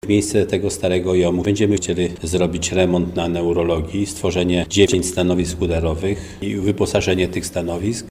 Lada dzień w stalowowolskim szpitalu powinna ruszyć rozbudowa udarówki. Pod potrzeby rozbudowy Oddziału Neurologicznego i Udarowego o sale udarowe zostaną zaadaptowane pomieszczenia po dawnym OIOM-ie. Mówi starosta stalowowolski Janusz Zarzeczny: